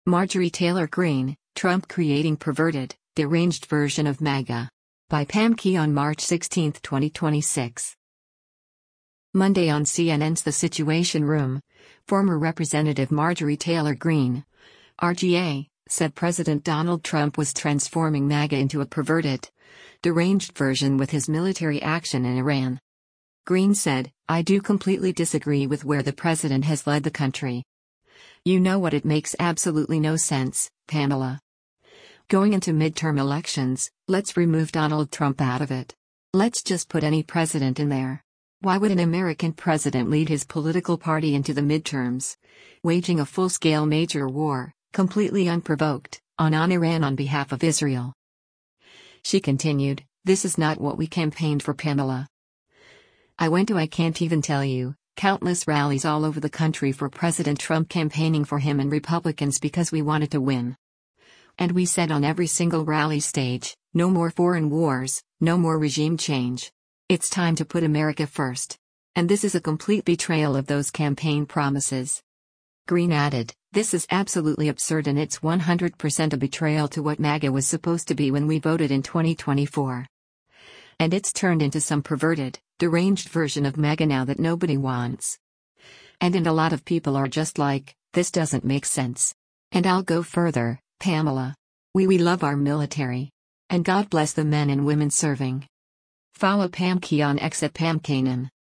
Monday on CNN’s “The Situation Room,” former Rep. Marjorie Taylor Greene (R-GA) said President Donald Trump was transforming MAGA into a “perverted, deranged version” with his military action in Iran.